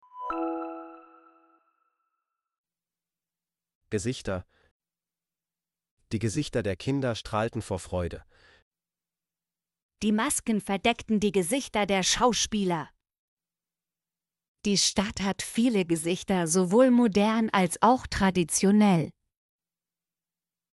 gesichter - Example Sentences & Pronunciation, German Frequency List